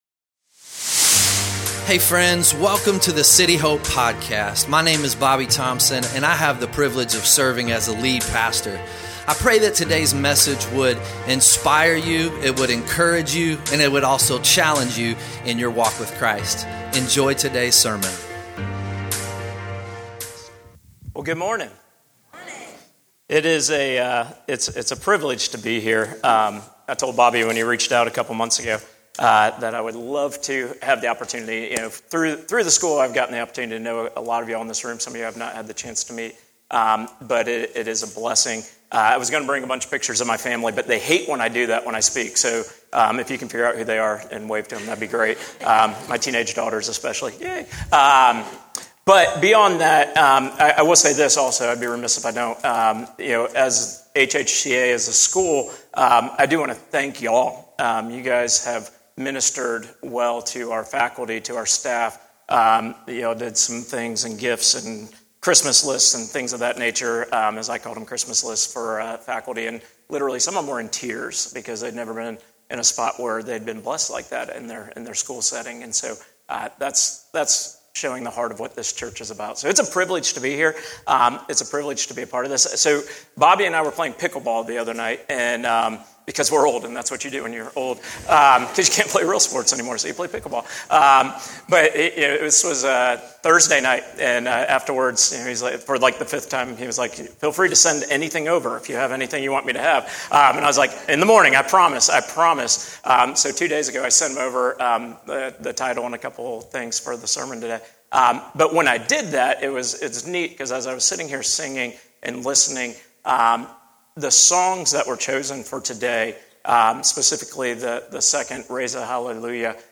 2024 Sunday Morning We had a special guest this week.